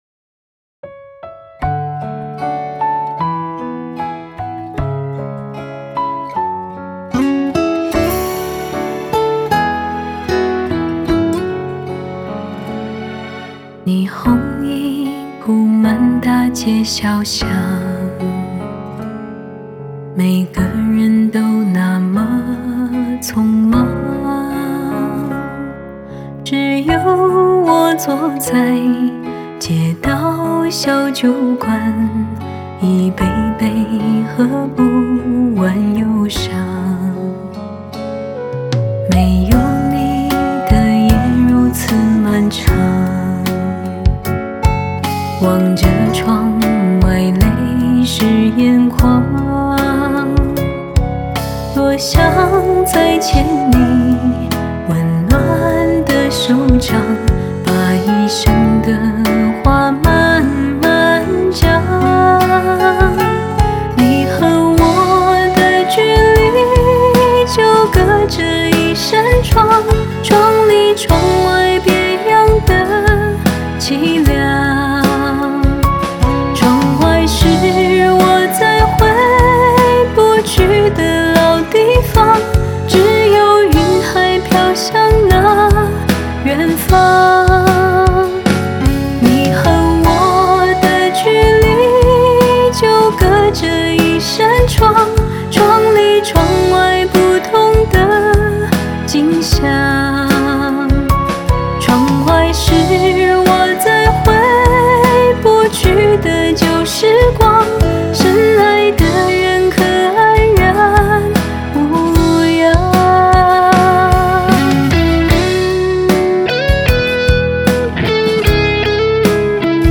Ps：在线试听为压缩音质节选，体验无损音质请下载完整版
吉他